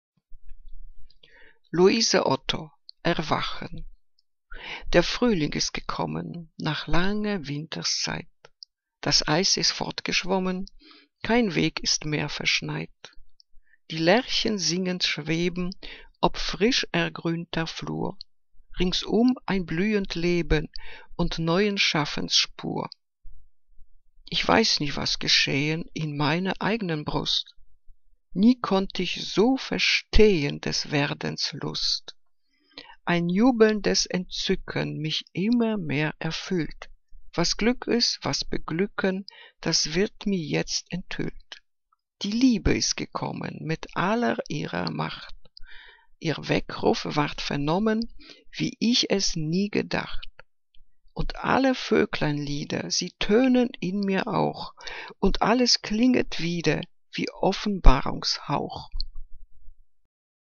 Liebeslyrik deutscher Dichter und Dichterinnen - gesprochen (Louise Otto)